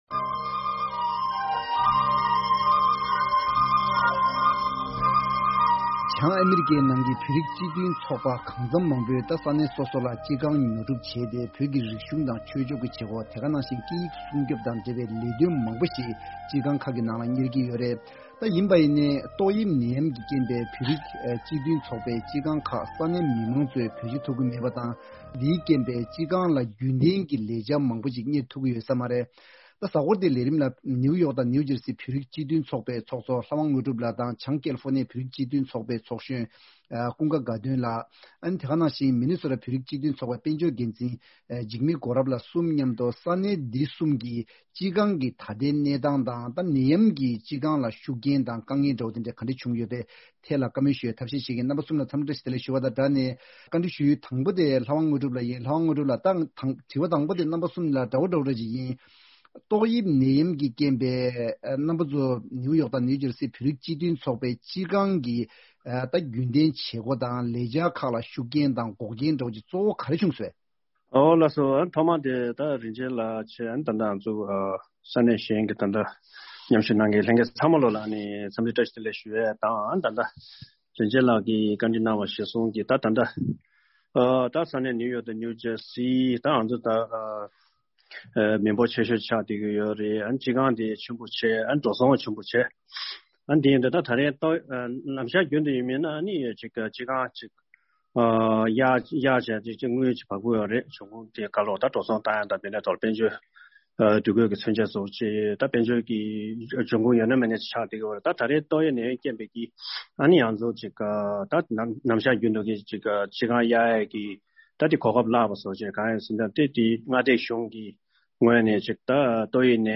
དེ་རིང་ང་ཚོས་ཏོག་དབྱིབས་ནད་ཡམས་ཀྱི་རྐྱེན་གྱིས་ཨ་རིའི་ནང་ཡོད་བོད་རིགས་སྤྱི་མཐུན་ཚོགས་པ་ཁག་གི་སྤྱི་ཁང་ཁག་གི་ནང་རྒྱུན་ལྡན་གྱི་བྱེད་སྒོ་སྤེལ་ཐུབ་ཀྱི་མེད་པ་མ་ཟད། འཆར་ཅན་དཔལ་འབྱོར་ཡོང་ཁུངས་ཉུང་དུ་སོང་ནས་འཛིན་སྐྱོང་ལ་ཤུགས་རྐྱེན་གང་འདྲ་བྱེད་ཀྱི་ཡོད་པའི་སྐོར་གླེང་མོལ་ཞུ་རྒྱུ་ཡིན།